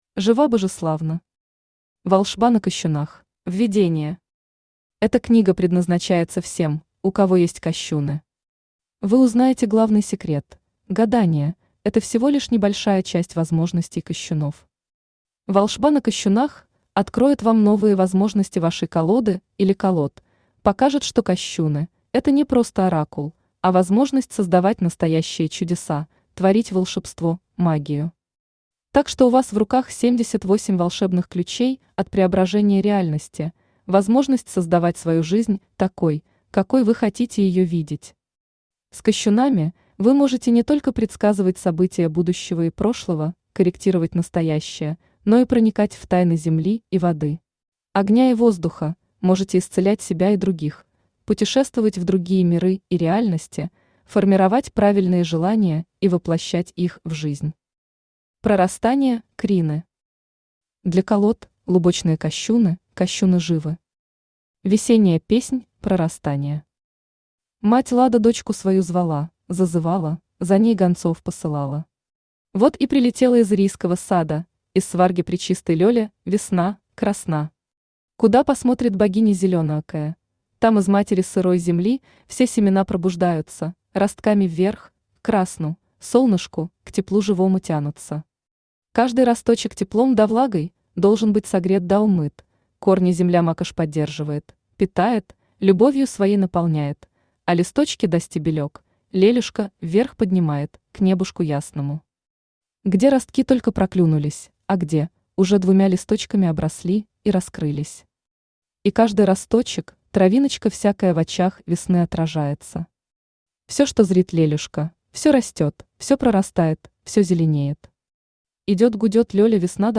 Аудиокнига Волшба на кощунах | Библиотека аудиокниг
Aудиокнига Волшба на кощунах Автор Жива Божеславна Читает аудиокнигу Авточтец ЛитРес.